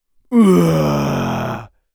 Male_Medium_Growl_01.wav